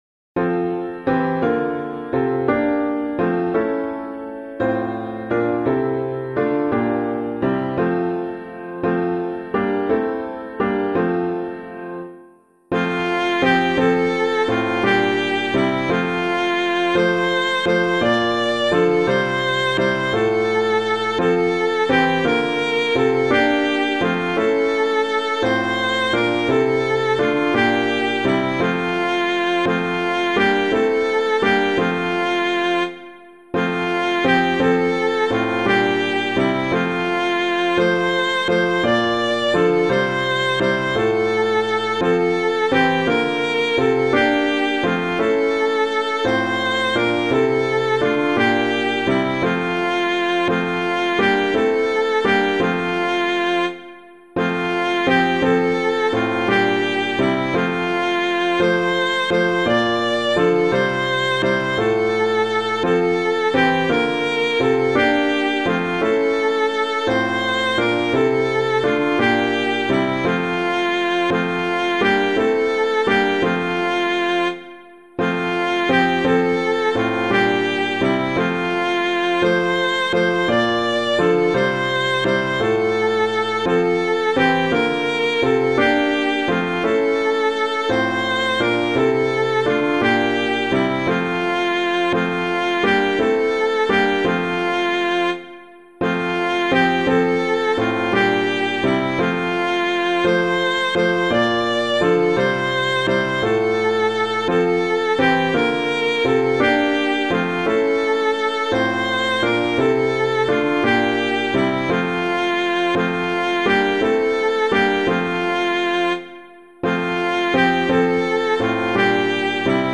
Christ the Lord Is Risen Again [Winkworth - ORIENTIS PARTIBUS] - piano.mp3